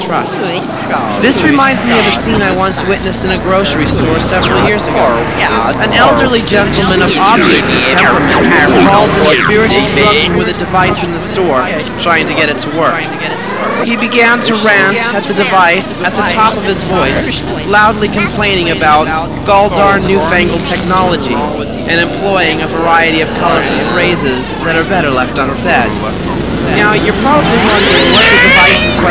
experimental, strange,and exotic sound